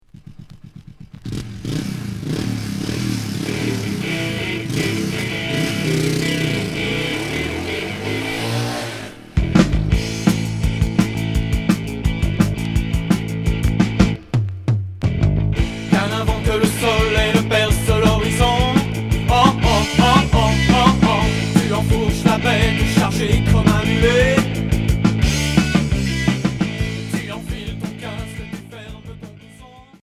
Hard rock